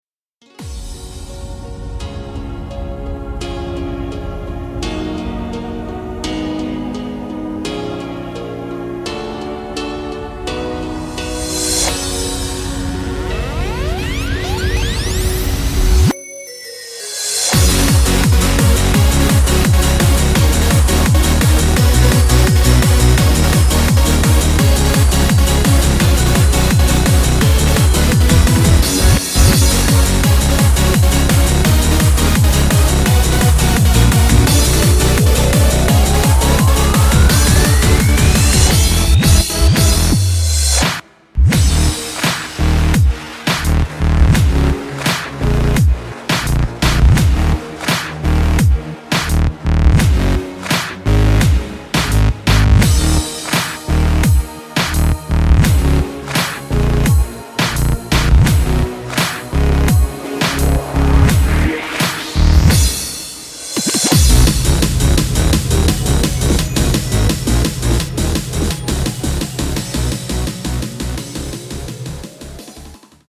OFF VOCAL VER.